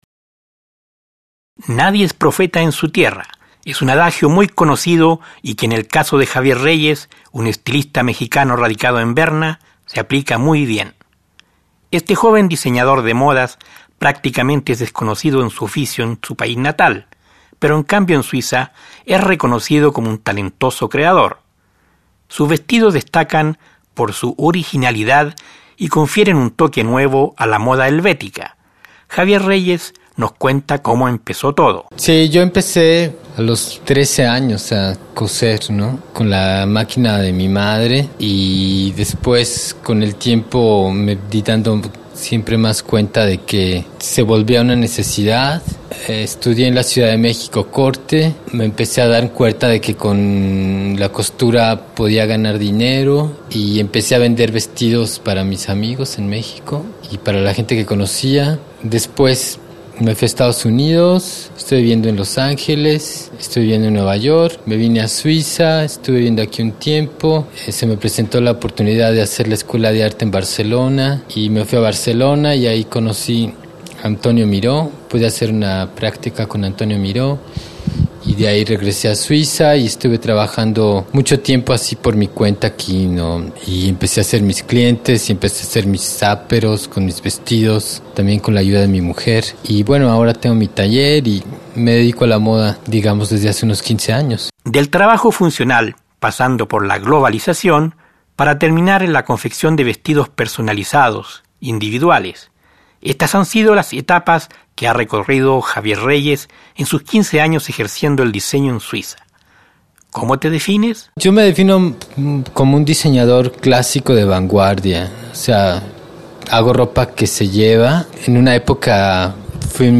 Un reportaje